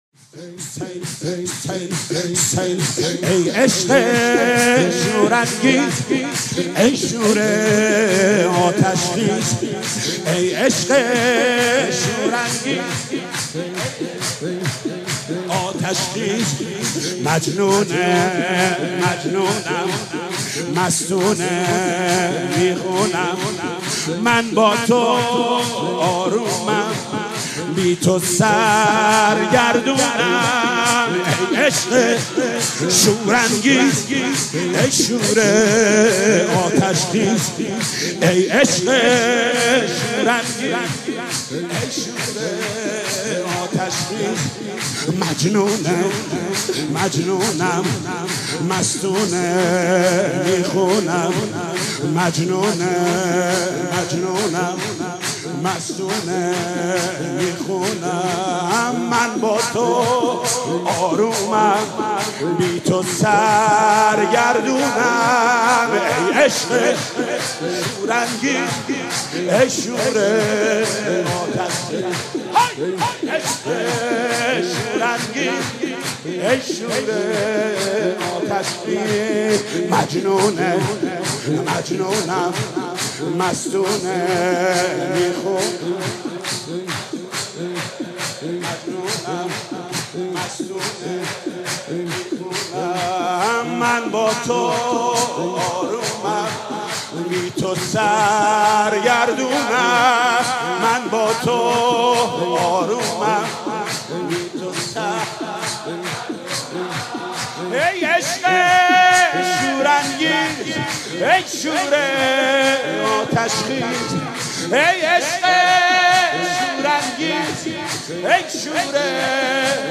سرود: ای عشق شورانگیز